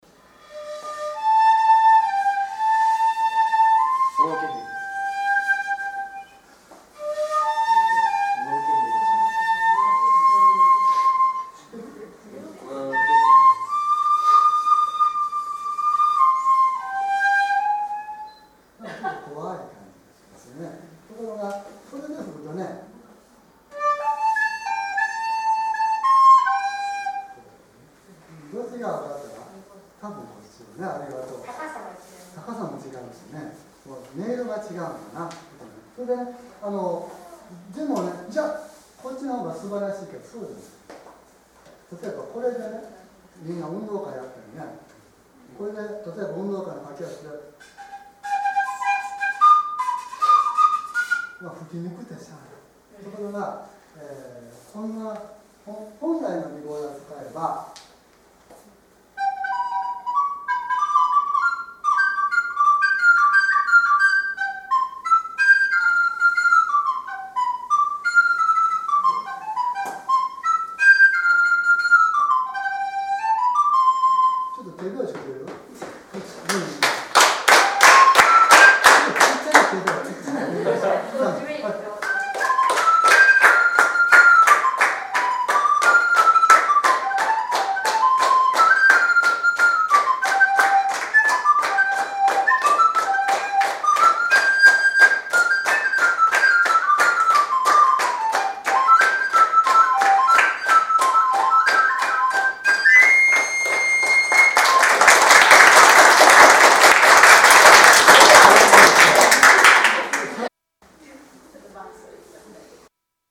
６．尺八とリコーダーに優劣は無く、ただ違いがあるだけ
せっかく尺－ダーを出してきましたので日本の楽器と西洋の楽器の違いを実際の音でお話ししました。